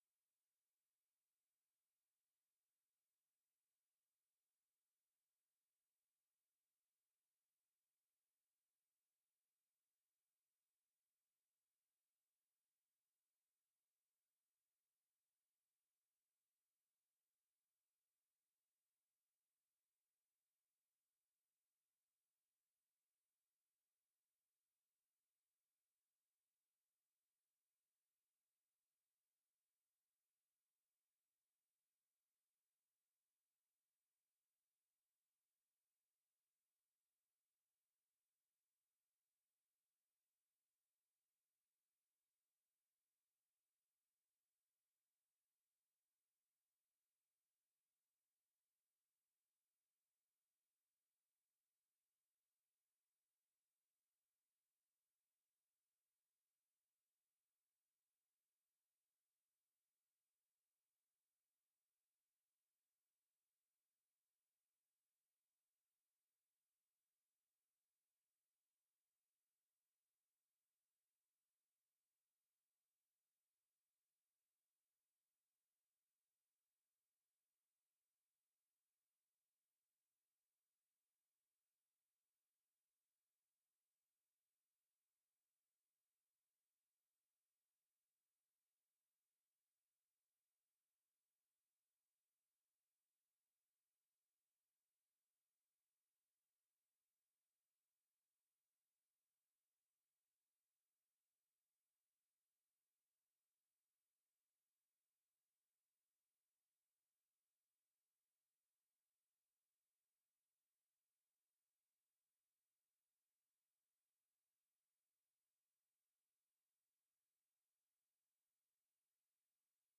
FR Le magazine en français https